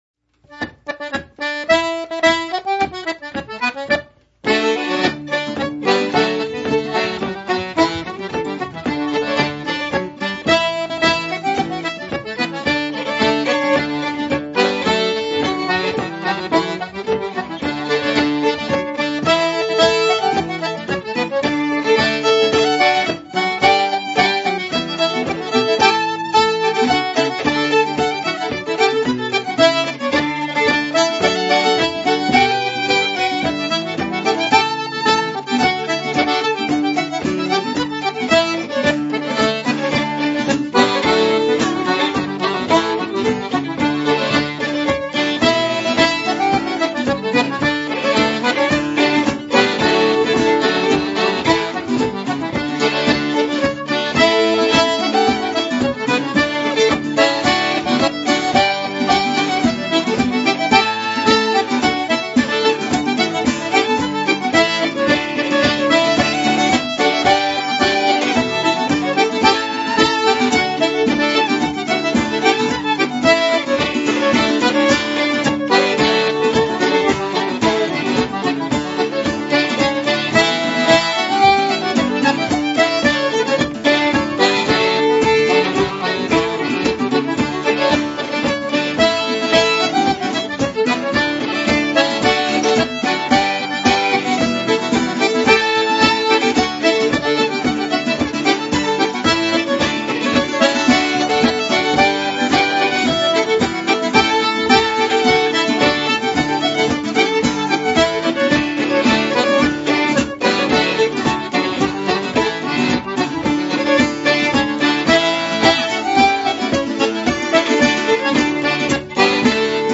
Below are links to mp3s of the FSC Country Dancing music as heard and used on camp.